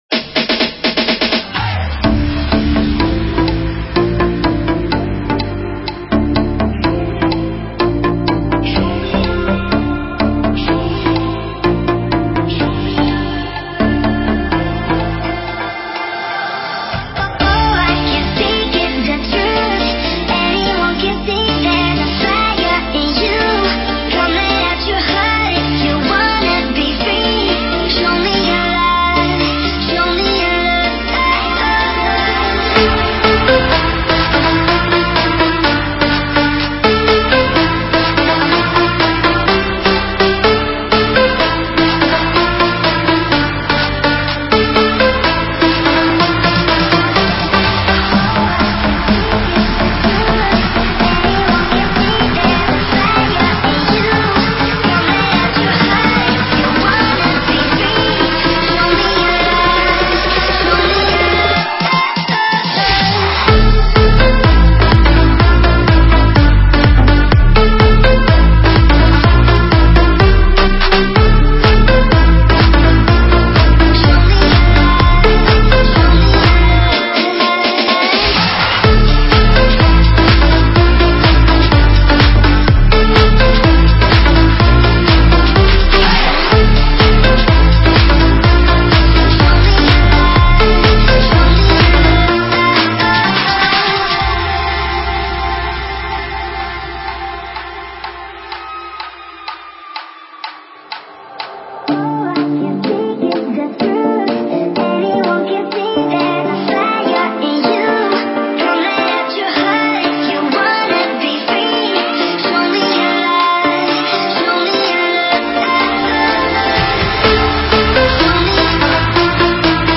Стиль: Танцевальная / Электронная музыка